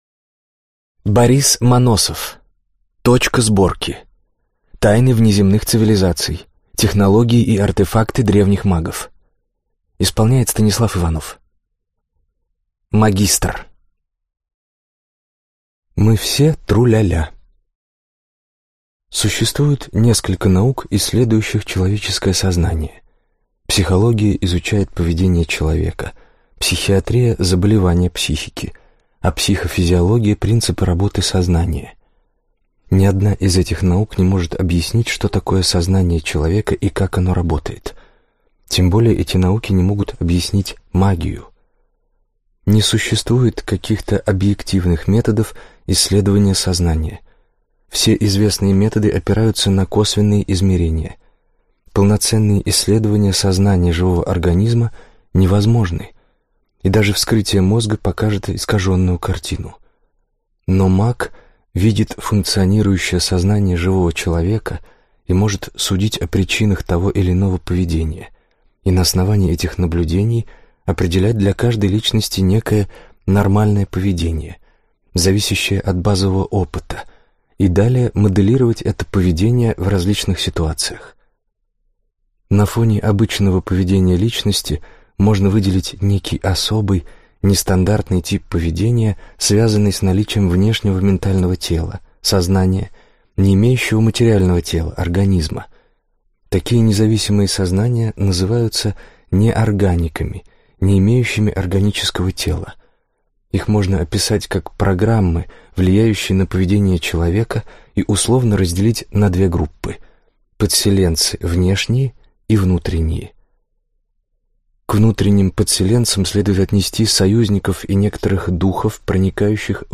Аудиокнига Точка сборки. Тайны внеземных цивилизаций. Технологии и артефакты древних магов | Библиотека аудиокниг